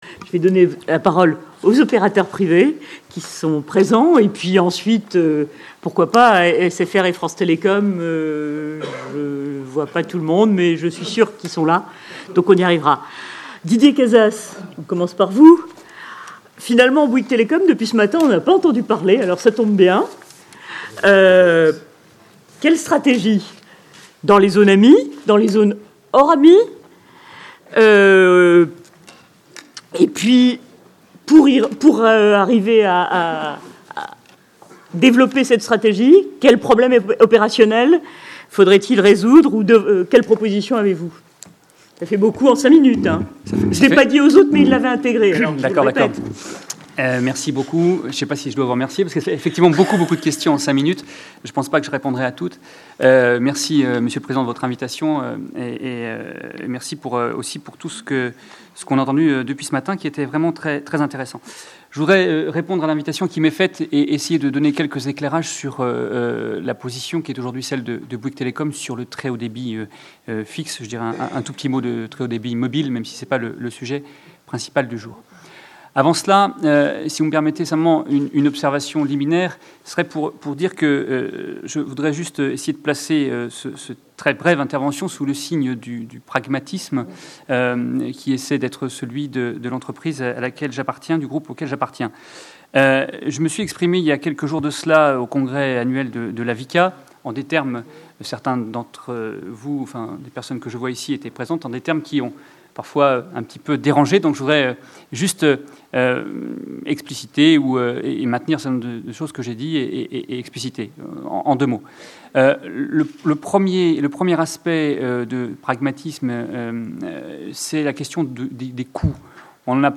a expliqué la position de Bouygues Telecom sur le très haut débit fixe lors de la Réunion plénière du Groupe d'échanges entre l'ARCEP, les collectivités territoriales et les opérateurs, le 6 décembre 2011.